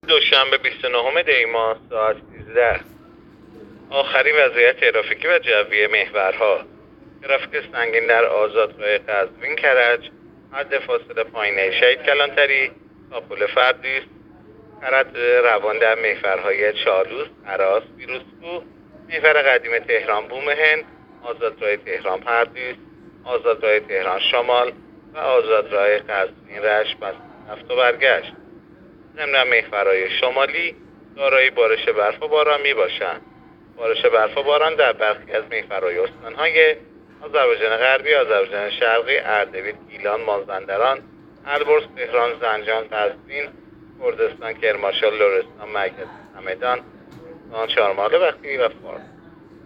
گزارش رادیو اینترنتی از آخرین وضعیت ترافیکی جاده‌ها ساعت ۱۳ بیست و نهم دی؛